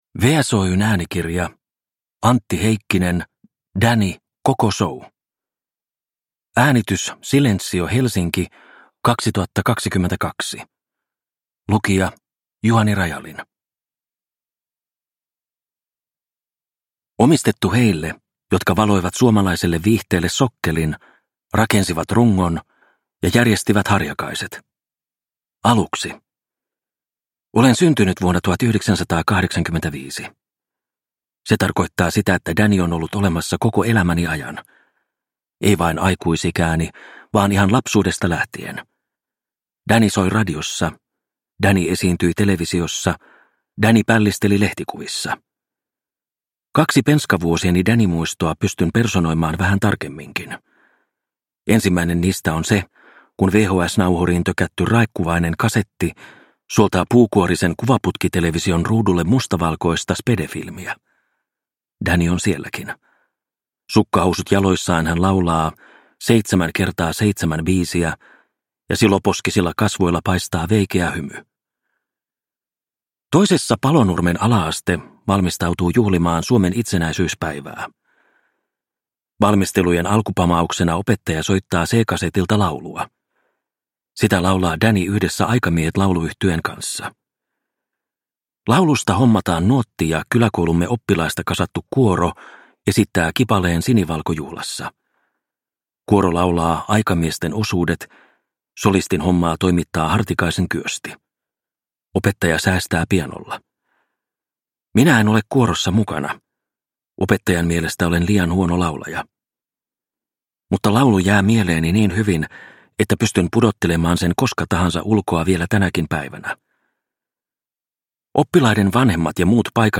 Danny - koko show (ljudbok) av Antti Heikkinen